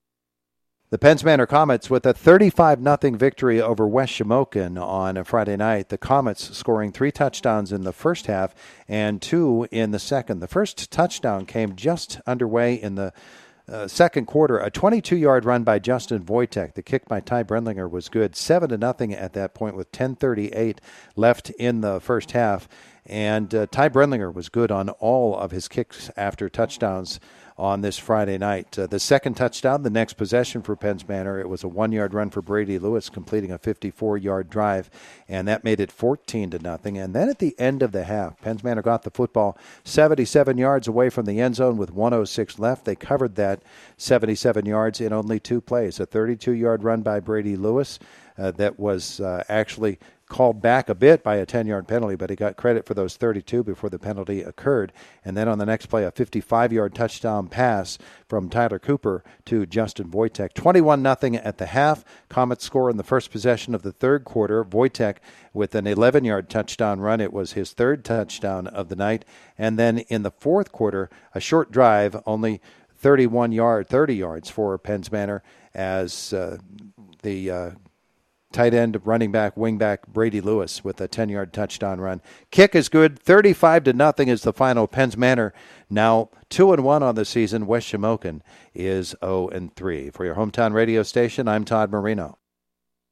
hsfb-west-shamokin-vs-penns-manor-recap.mp3